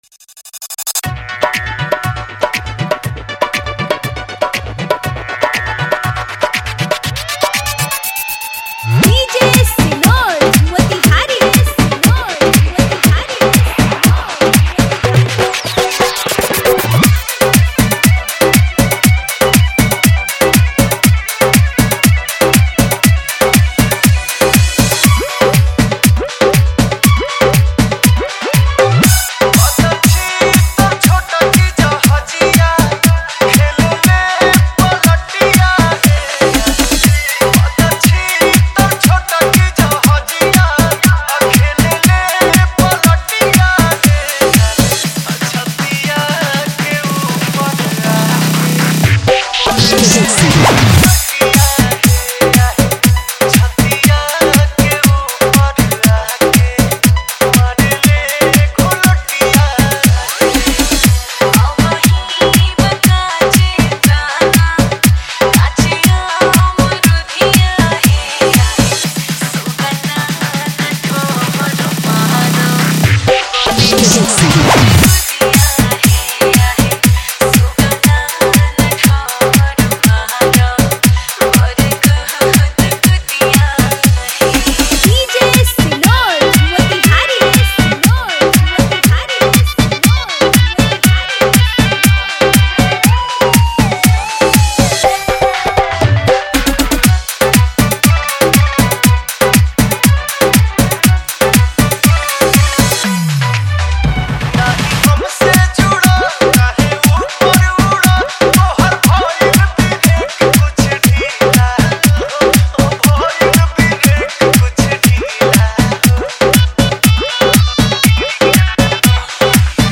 Bhojpuri All Dj Song